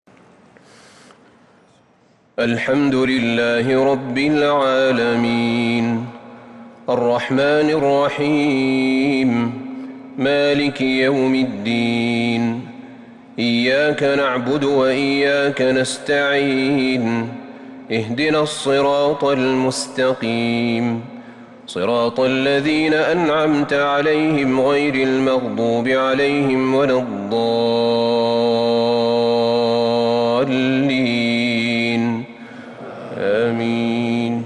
سورة الفاتحة من تراويح الحرم النبوي 1442هـ > مصحف تراويح الحرم النبوي عام 1442هـ > المصحف - تلاوات الحرمين